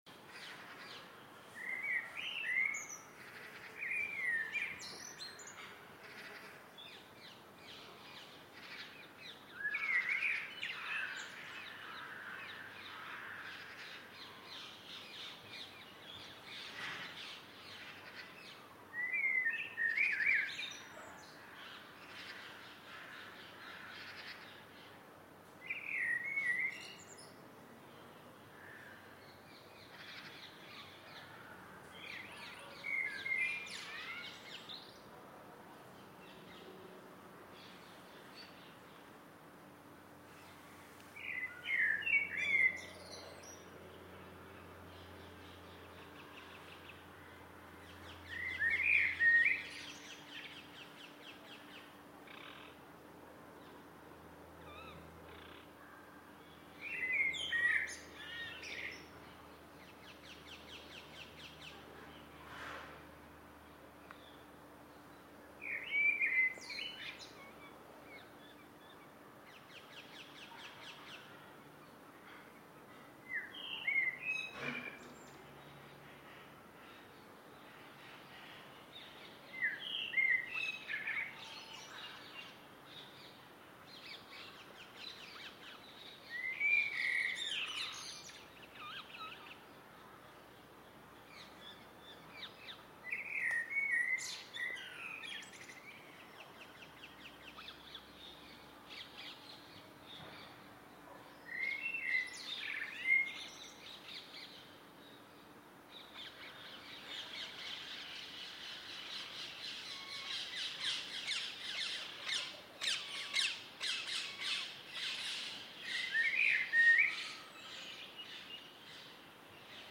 Evening Garden Birdsong 23 june 2021
This stereo soundscape includes a blackbird, parakeets and, at one point, a buzzy creature near the microphone. Good headphones or speakers will give the best stereo effect.